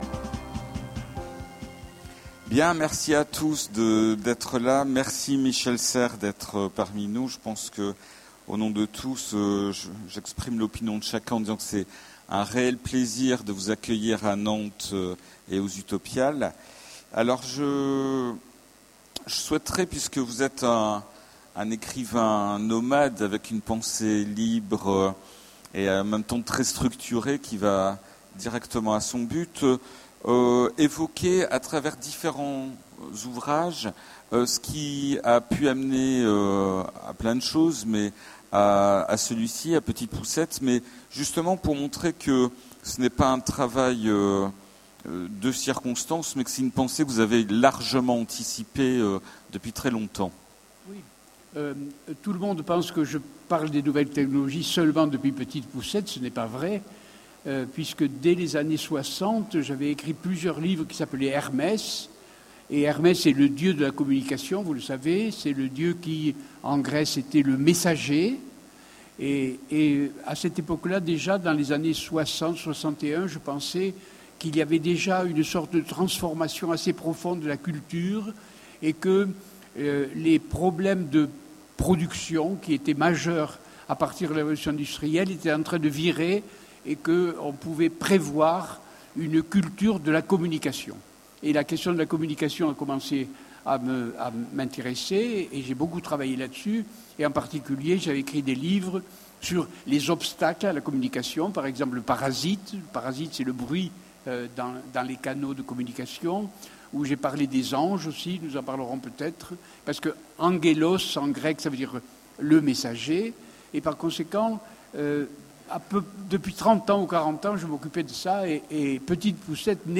Utopiales 13 : Conférence Rencontre avec Michel Serres
- le 31/10/2017 Partager Commenter Utopiales 13 : Conférence Rencontre avec Michel Serres Télécharger le MP3 à lire aussi Michel Serres Genres / Mots-clés Philosophie Rencontre avec un auteur Conférence Partager cet article